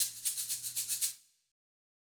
Shaker 11.wav